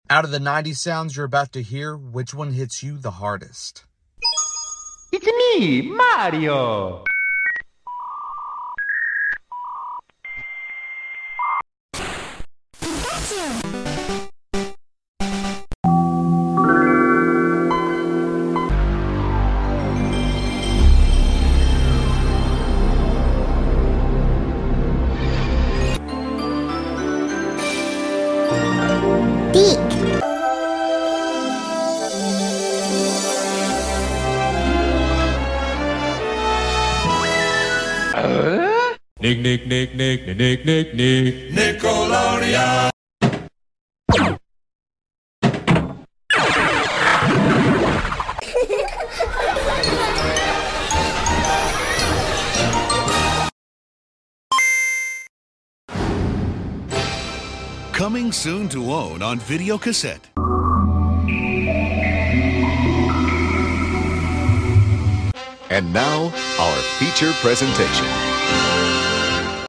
Out of the 90’s era sound effects free download